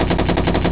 Biblioteca de sons » Sons » Sons de guerra
metralhadora1.au